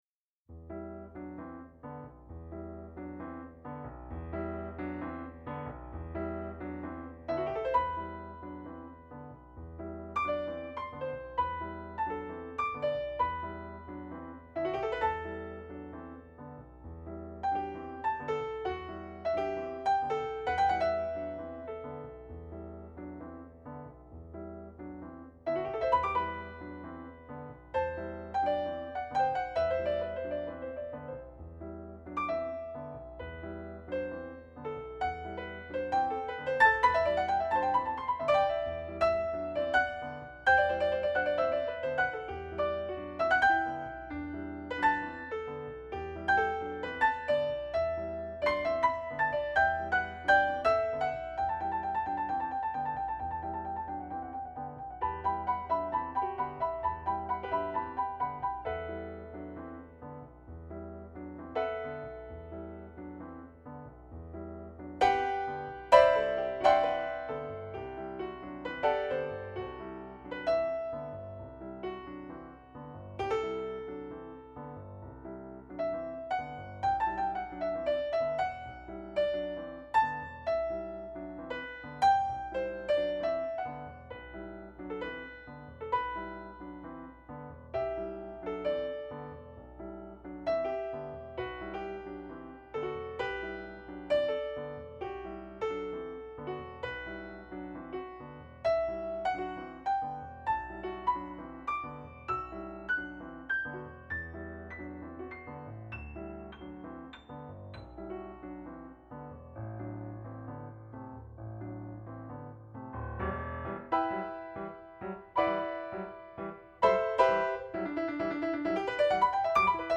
Yamaha Disklavier Duration: 5 min.
a quasi-tango for mechanical piano